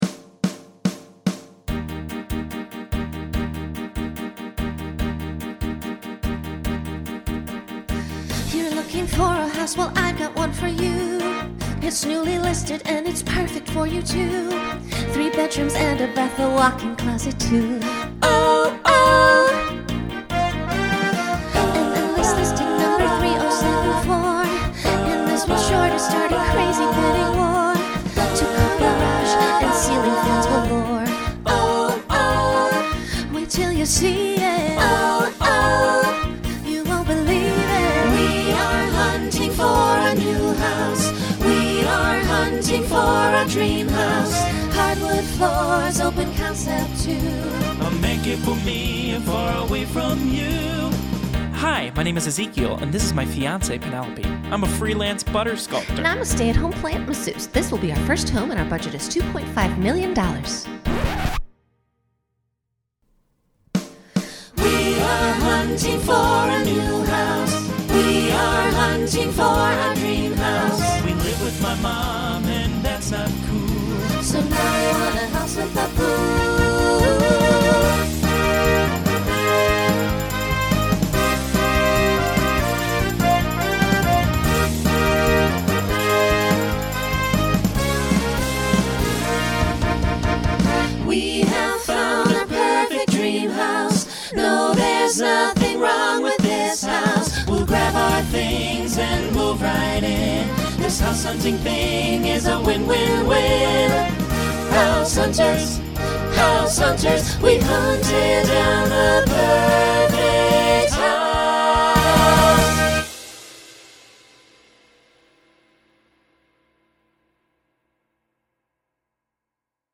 parody
Genre Rock Instrumental combo
Story/Theme Voicing SATB